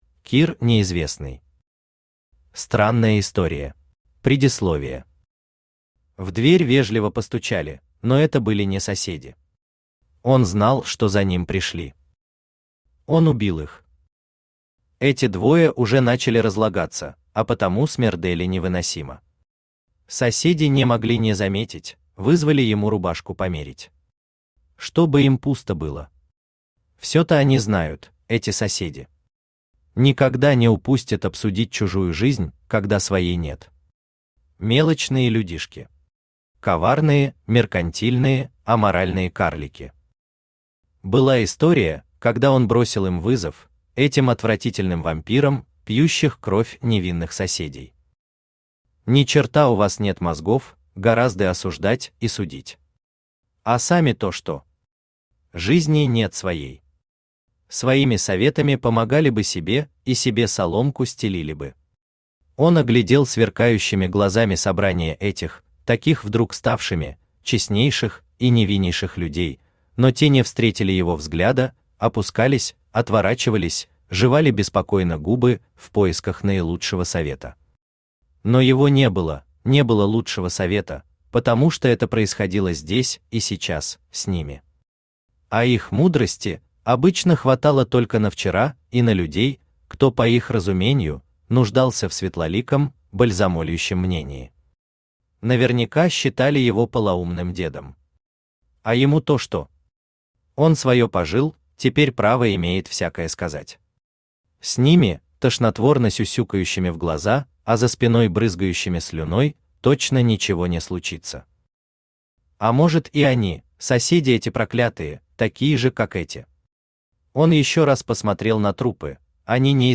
Аудиокнига Странная история | Библиотека аудиокниг
Aудиокнига Странная история Автор Кир Николаевич Неизвестный Читает аудиокнигу Авточтец ЛитРес.